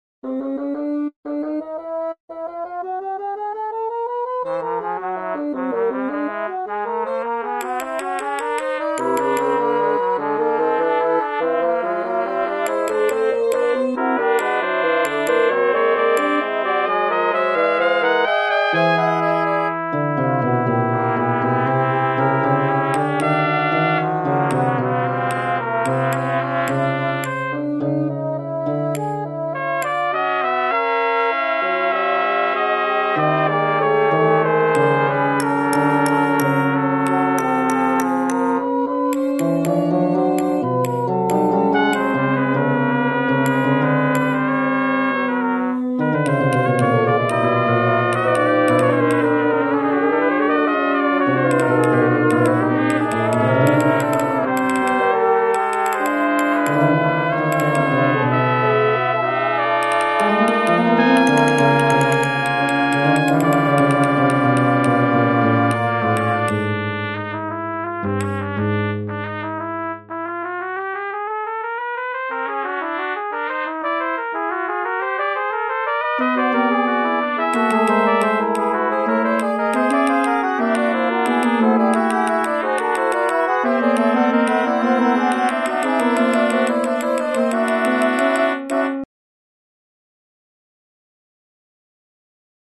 Terzi di tono